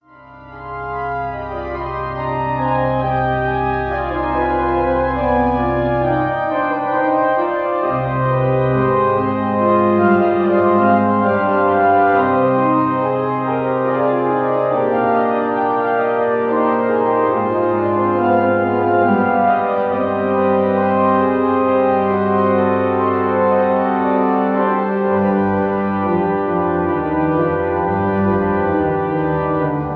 organ
the cathedral of Saint-Jean, Lyon